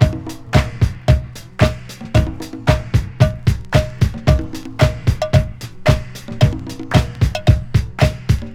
• 112 Bpm Modern Dance Drum Loop Sample B Key.wav
Free drum groove - kick tuned to the B note. Loudest frequency: 561Hz
112-bpm-modern-dance-drum-loop-sample-b-key-KgI.wav